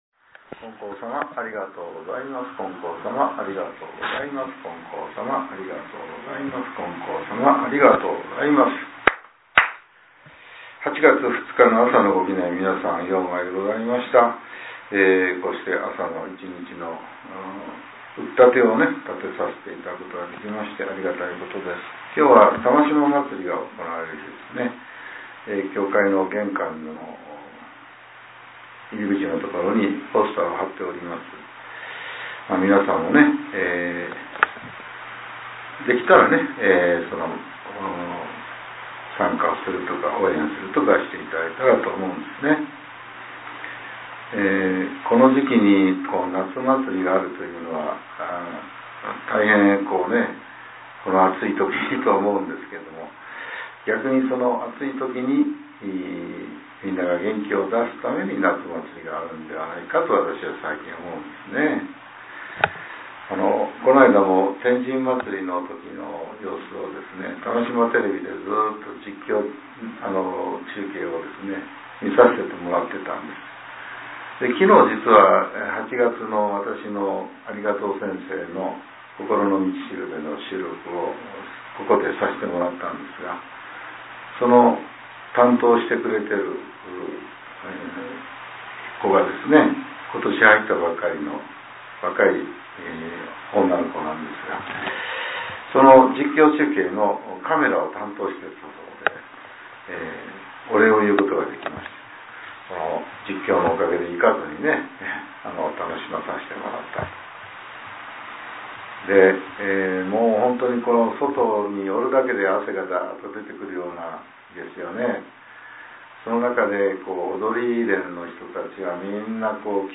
令和７年８月２日（朝）のお話が、音声ブログとして更新させれています。